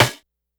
Snare_16.wav